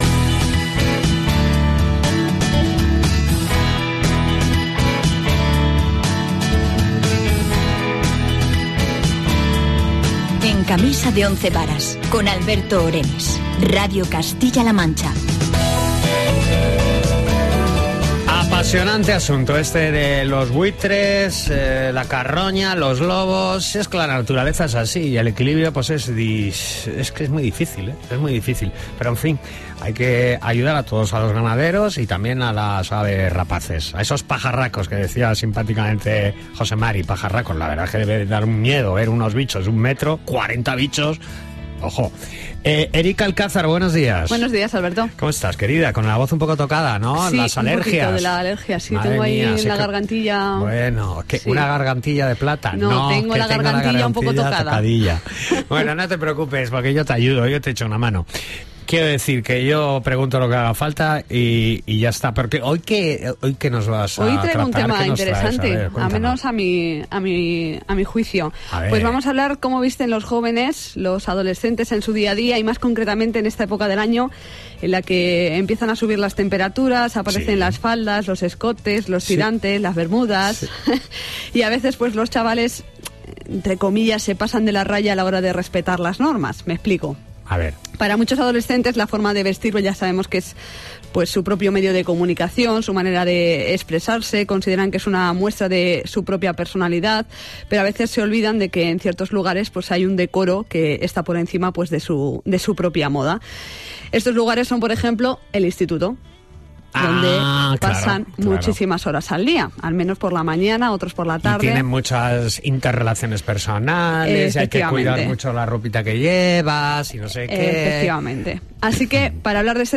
reportaje-vestimenta-colegios.mp3